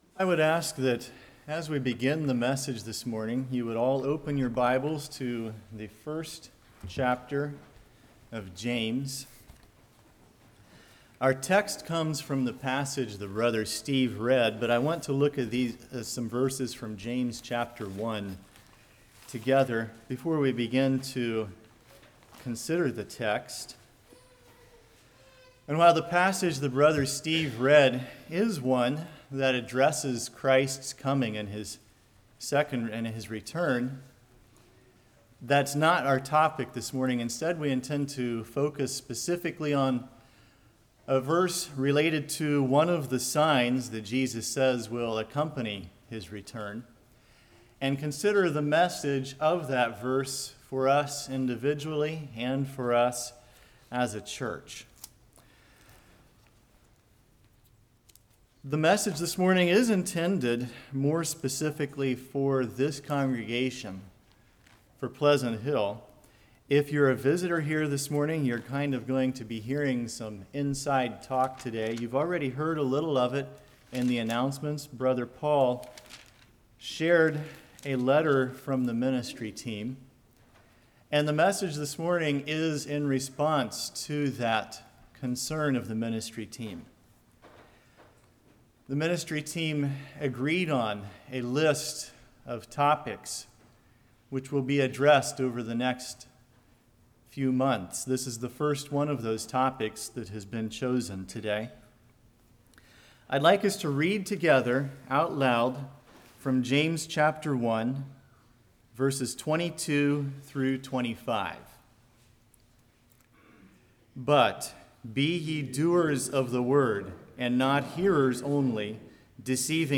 Matthew 25:1-13 Service Type: Morning Iniquity Love Meaning for us « Is God Pleased With Your Life?